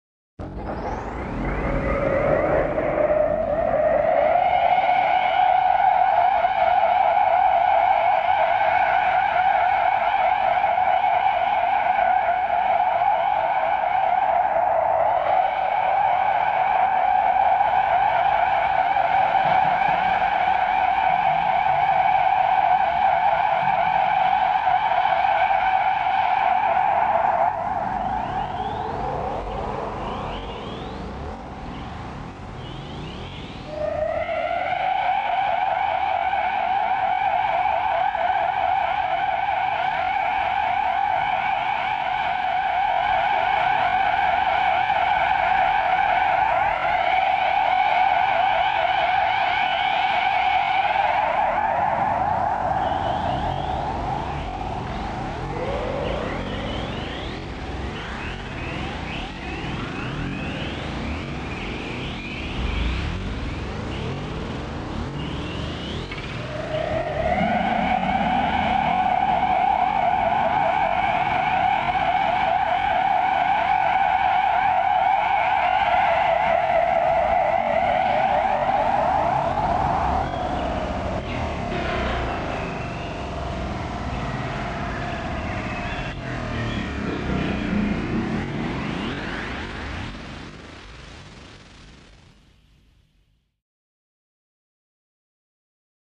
На этой странице собраны звуки, которые ассоциируются с инопланетянами и пришельцами: странные сигналы, электронные помехи, \